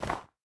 snow4.ogg